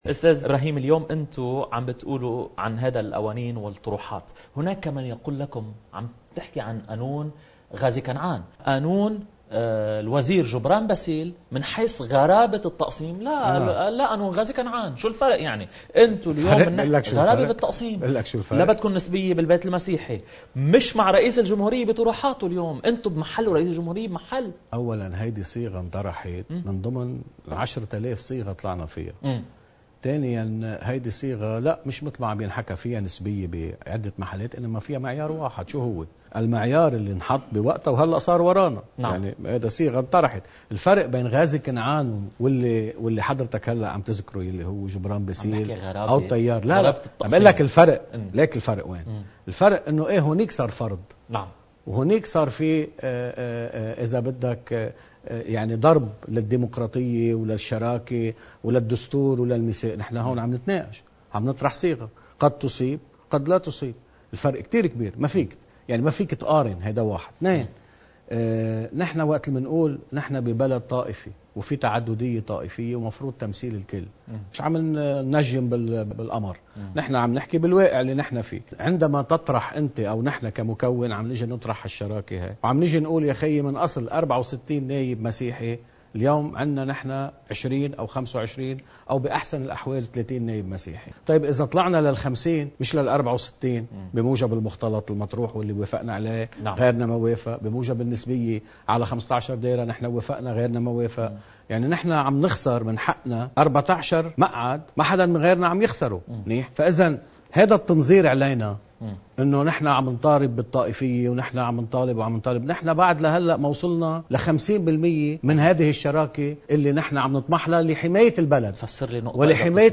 مقتطف من حديث النائب إبراهيم كنعان لقناة الـ”nbn”